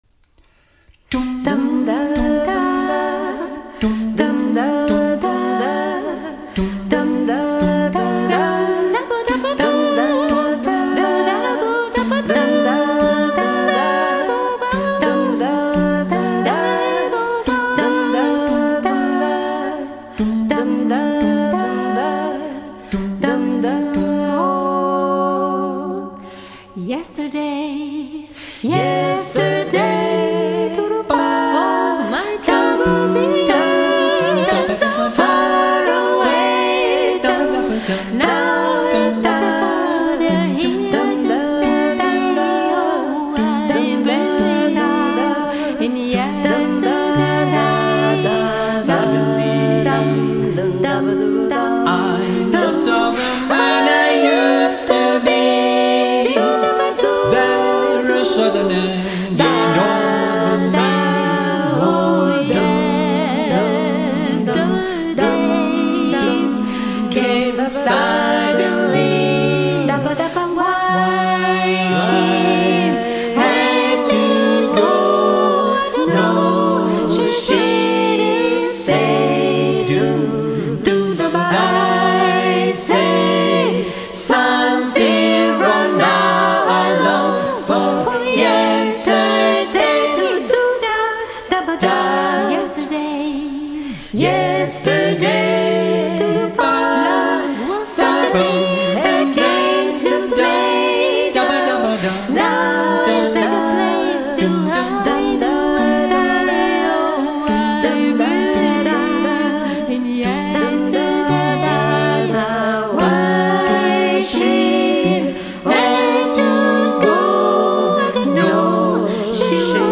(SAAT)